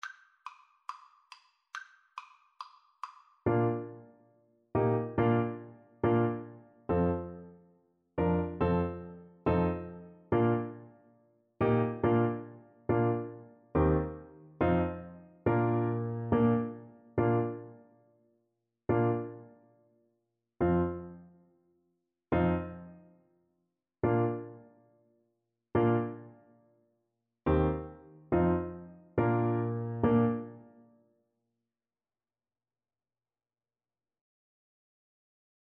Fast = c. 140